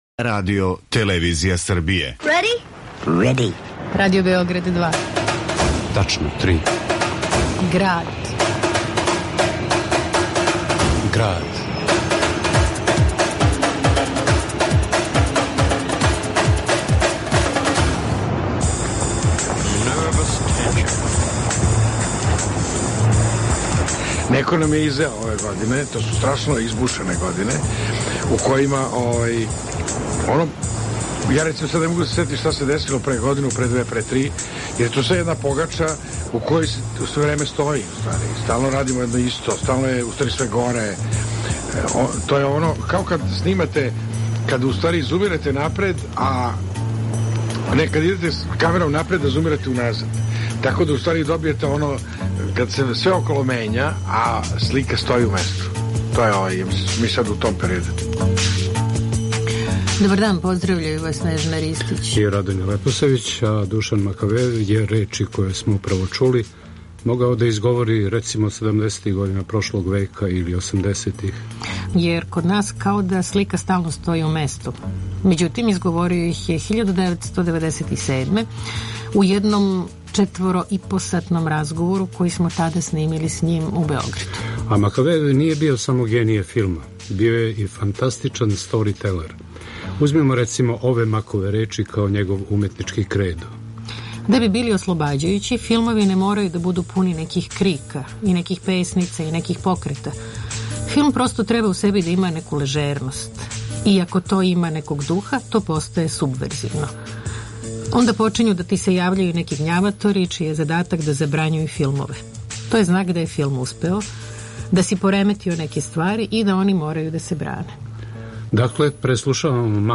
У Граду , Душан Макавејев на снимцима из 1997: о Београду, Загребу, Крлежи, Миховилу Пансинију, филмовима, политици...